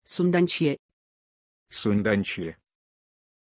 Aussprache von Sundance
pronunciation-sundance-ru.mp3